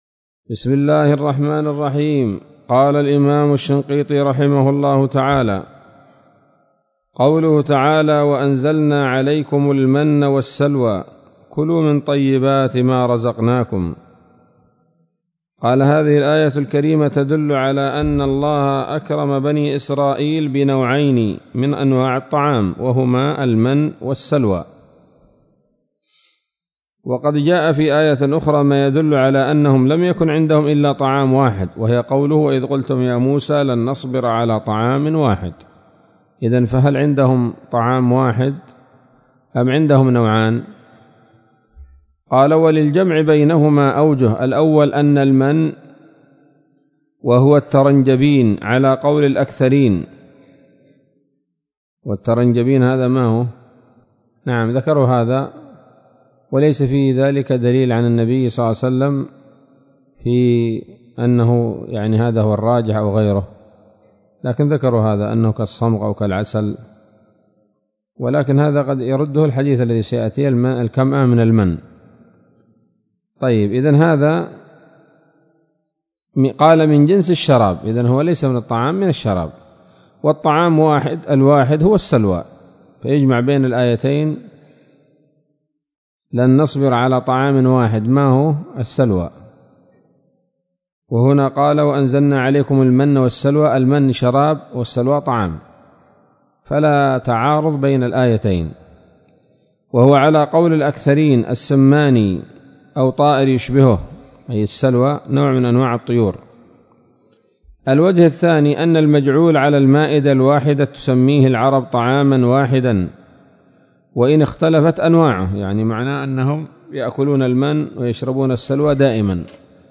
الدرس الحادي عشر من دفع إيهام الاضطراب عن آيات الكتاب